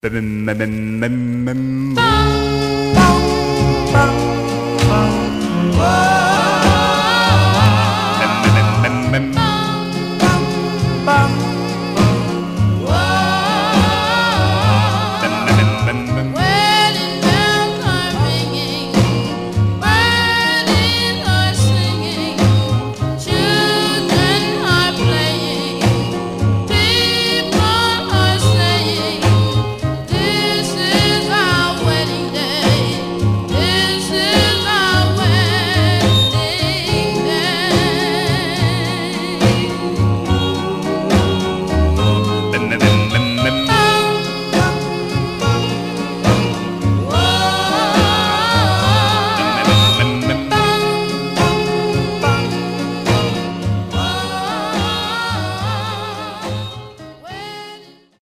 Condition Some surface noise/wear Stereo/mono Mono
Male Black Groups